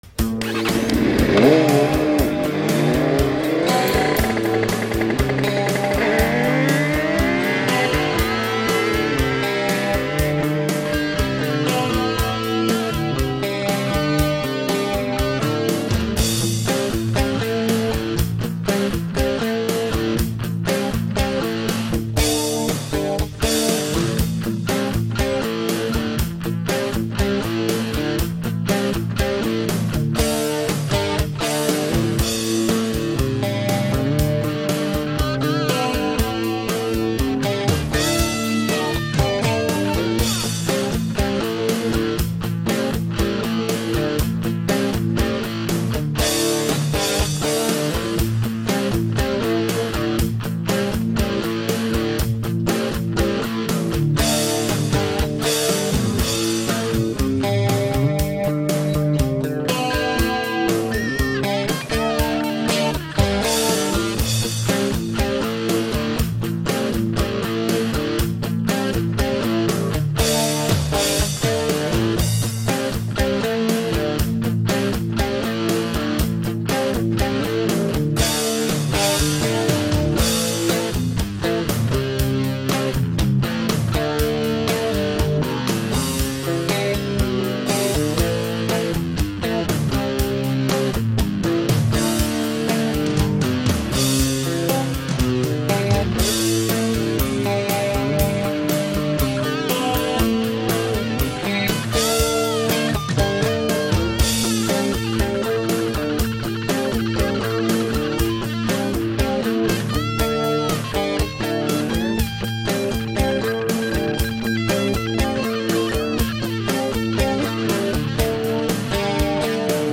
Original Song - Addiction (Instrumental)
rock guitar